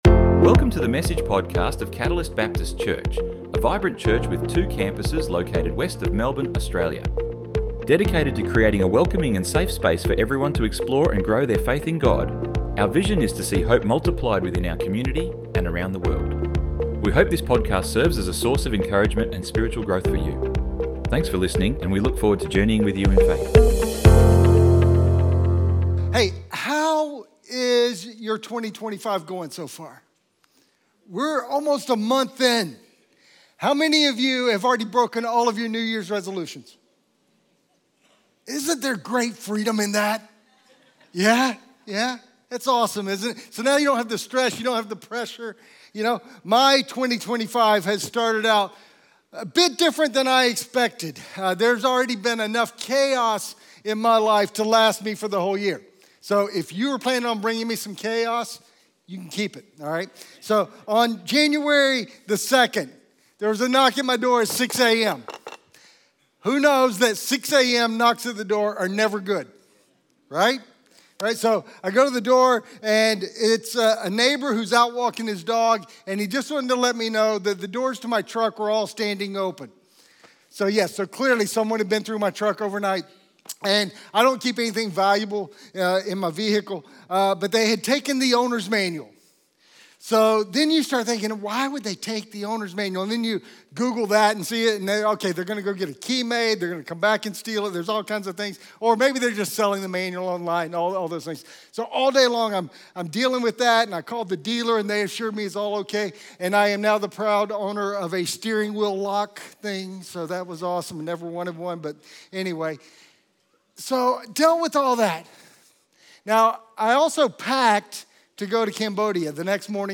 Download Download Sermon Notes 05 - online notes - Unshakable Faith - Even if.doc It is easy to have faith when life is great and things are smooth.